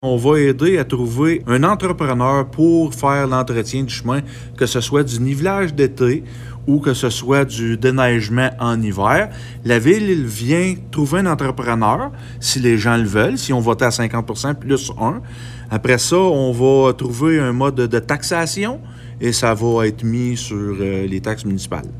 Le conseil municipal a récemment adopté un règlement définissant l’accompagnement offert. Comme l’explique le maire, Mathieu Caron, la Ville compte de nombreux chemins privés et se devait d’aider à les entretenir.